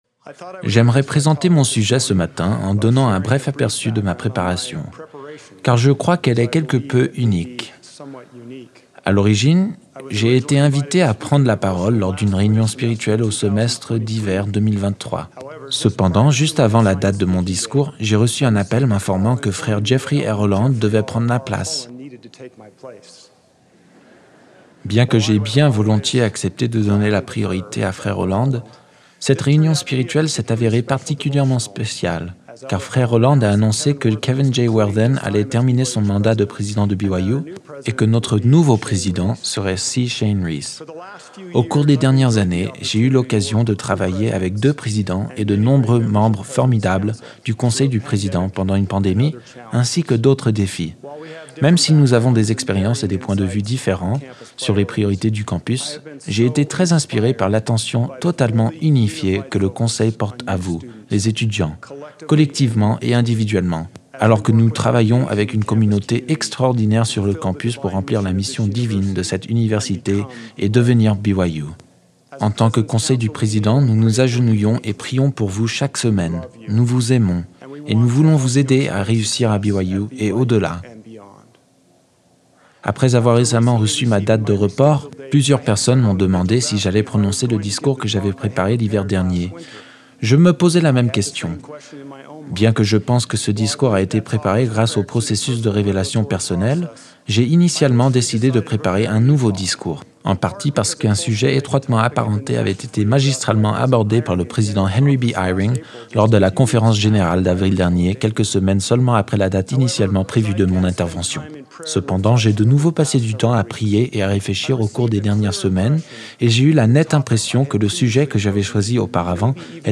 FRA_Our-Path-to_Audio-with-ENG-background.mp3